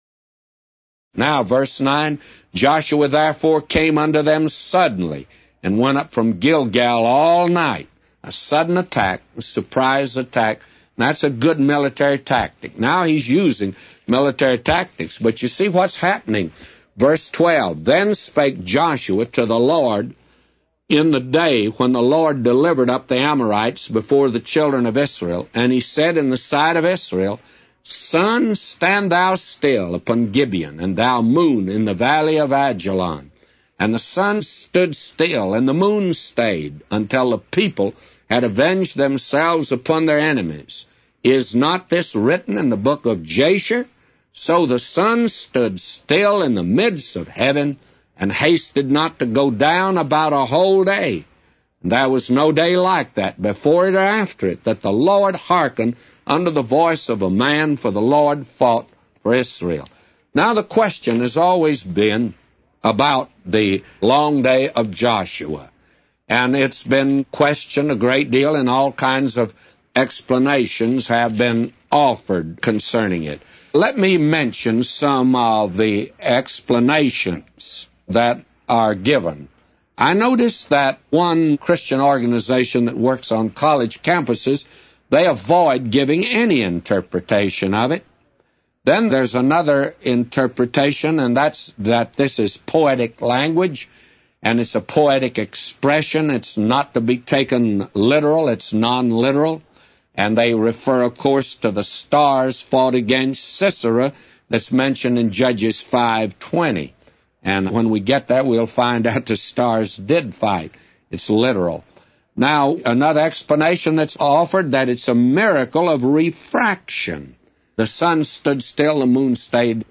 A Commentary By J Vernon MCgee For Joshua 10:1-999